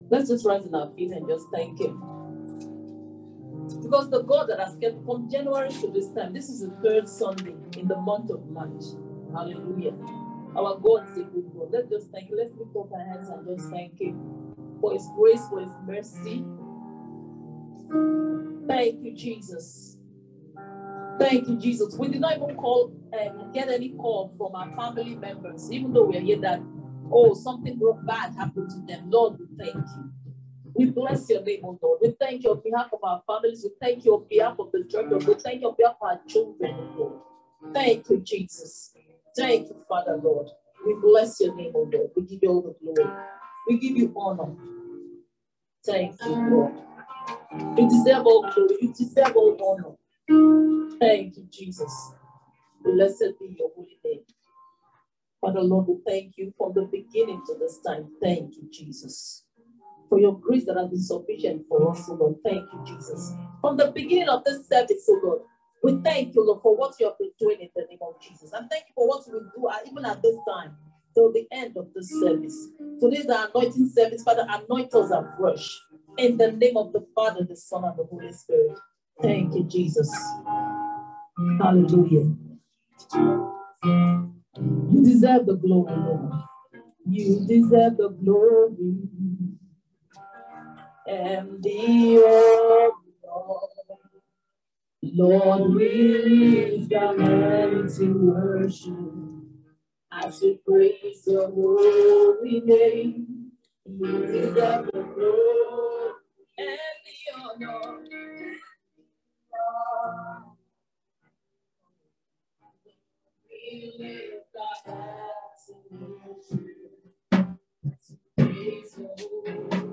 March-Anointing-Service.mp3